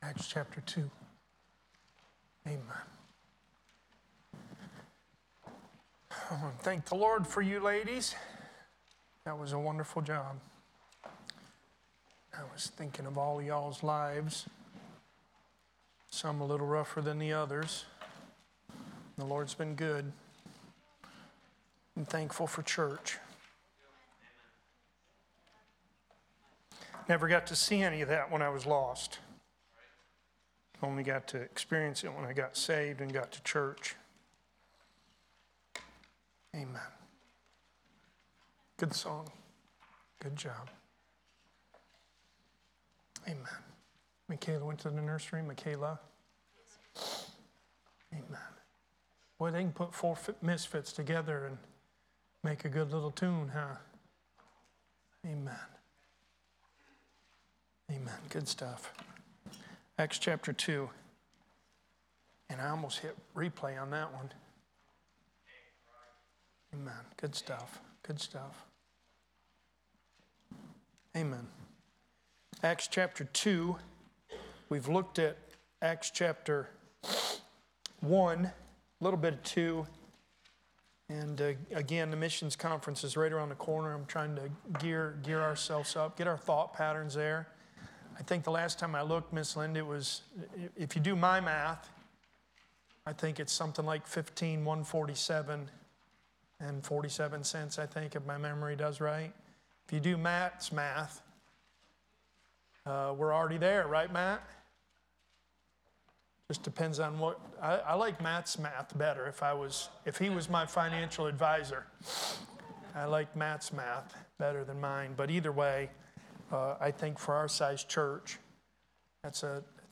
Sermons | Graham Road Baptist Church